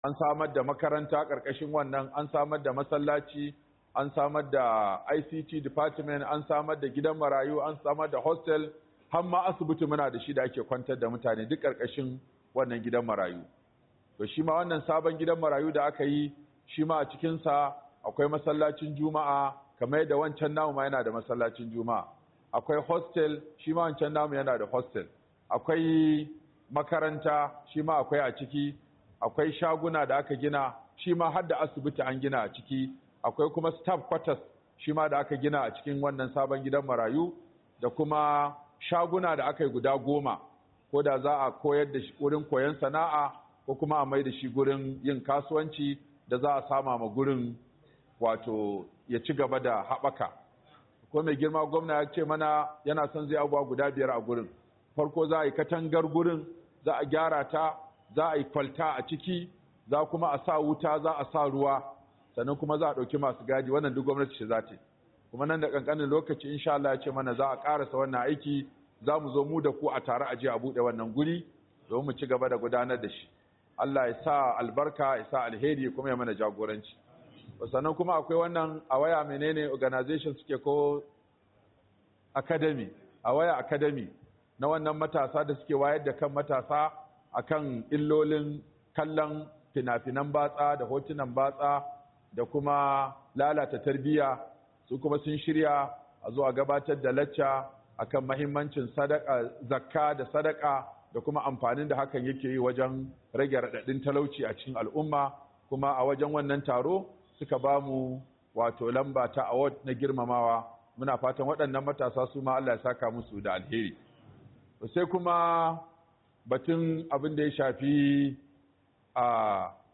Manufar Wakafi - MUHADARA by Sheikh Aminu Ibrahim Daurawa